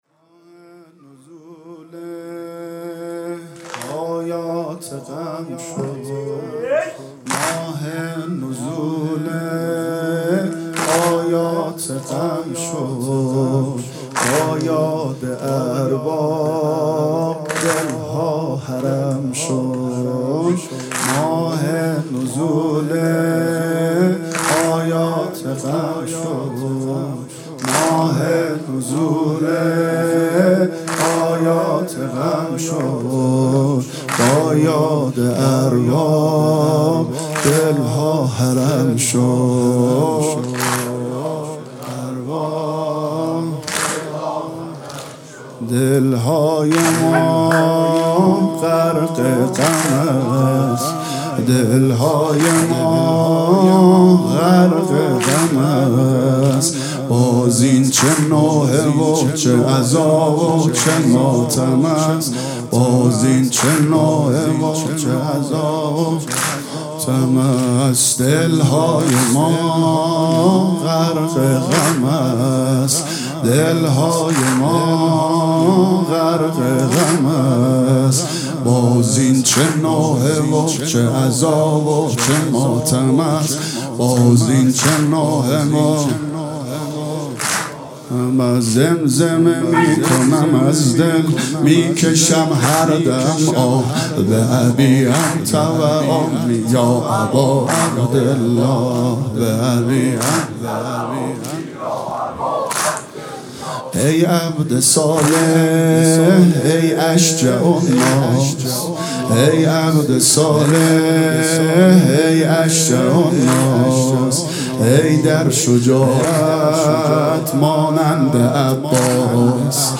صوت مراسم شب اول محرم